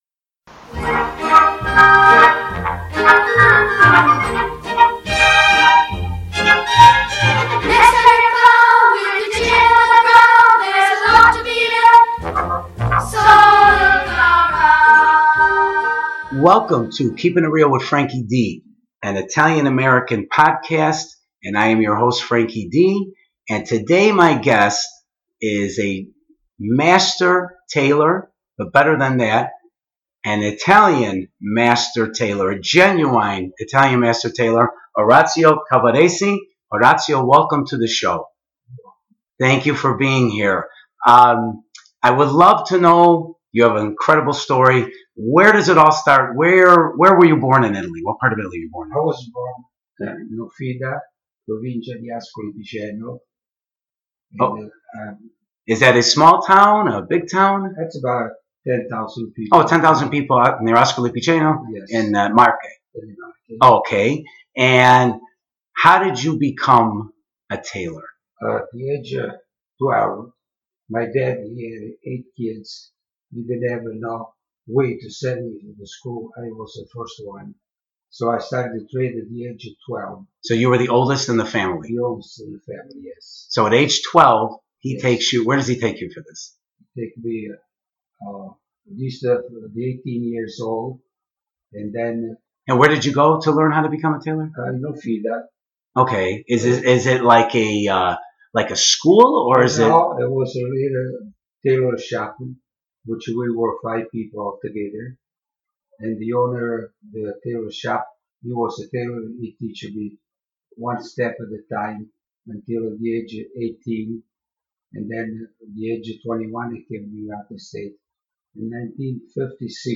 Episode 18 – Interview